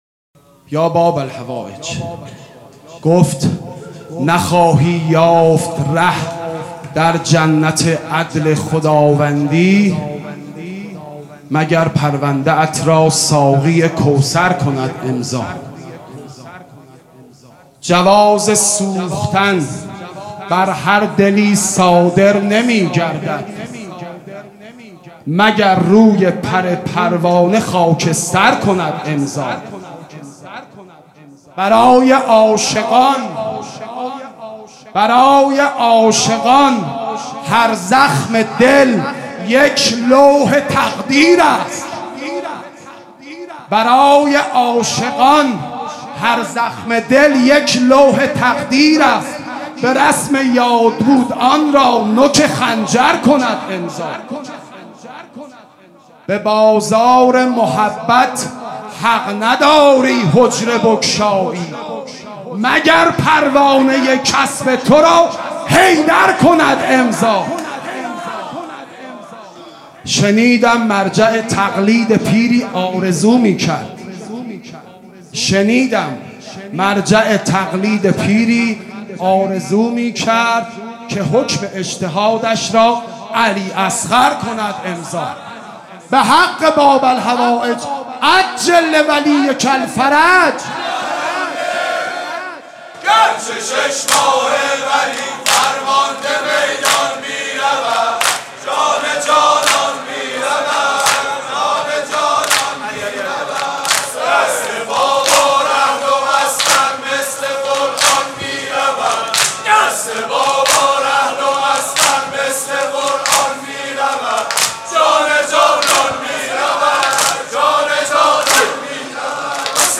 شب هفتم محرم 97 - دودمه